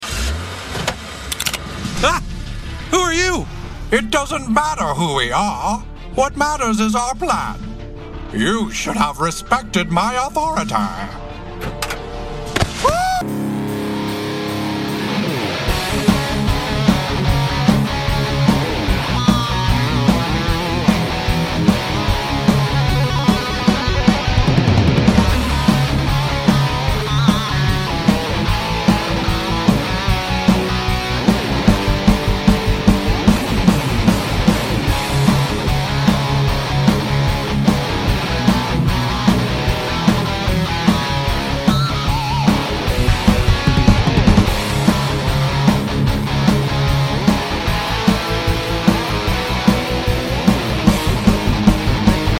Tags: Adventure Show Podcast Talk Radio Funny